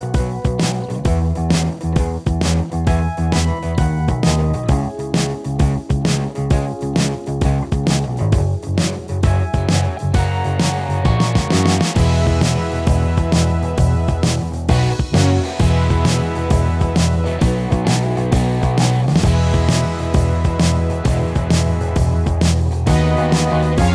Minus Lead Guitar Rock 4:22 Buy £1.50